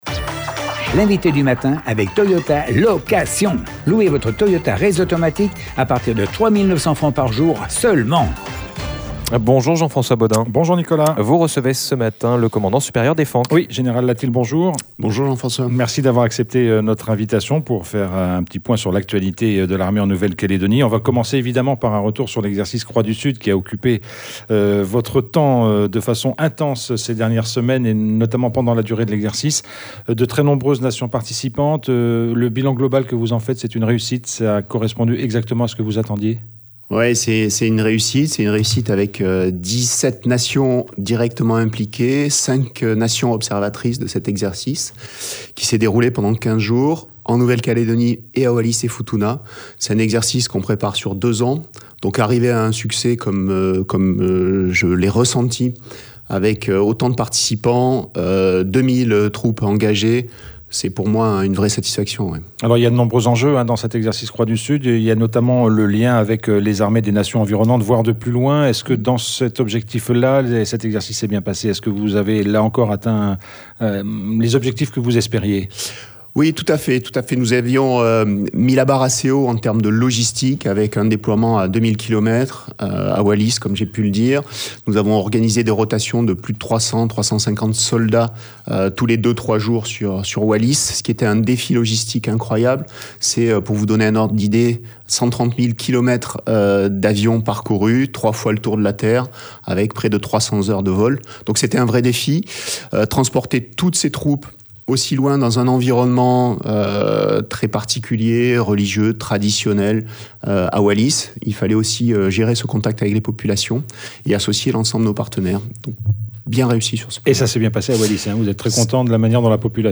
L'INVITE DU MATIN : GENERAL LATIL
Notre invité était le général Latil, commandant supérieur des FANC. L’occasion de revenir sur l’exercice Croix du Sud qui s’est refermé la semaine dernière.